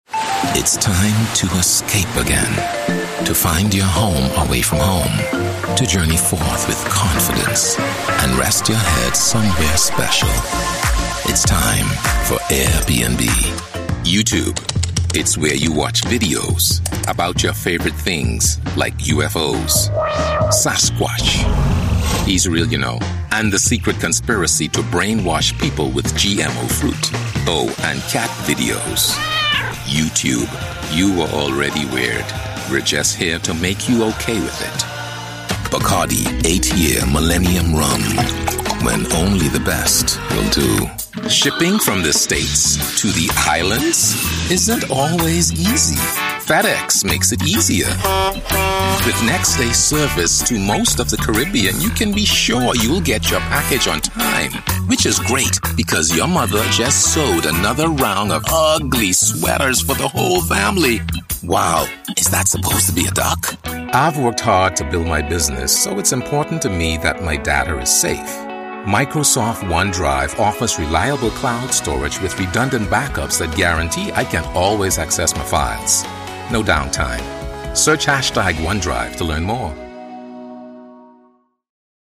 Englisch (Karibik)
Warmherzig, neutral und stark.
Vertrauenswürdig
Autorisierend